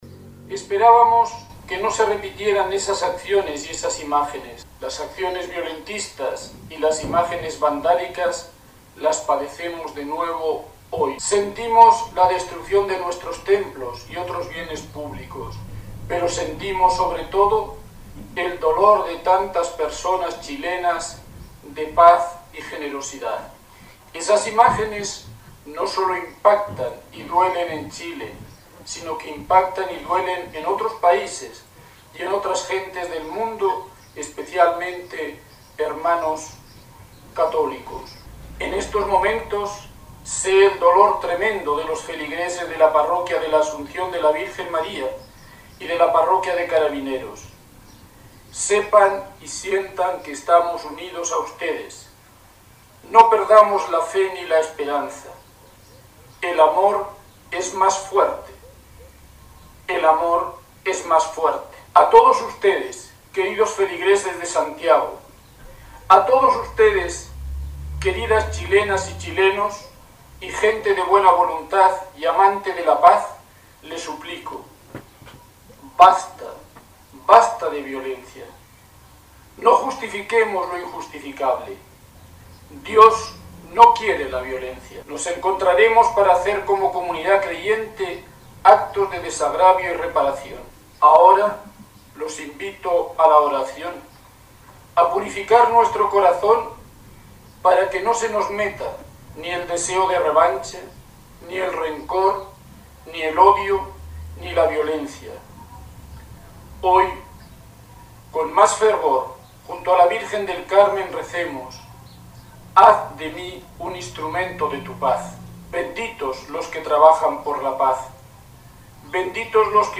Arzobispo de Santiago se refiere a los ataques sufridos por parroquias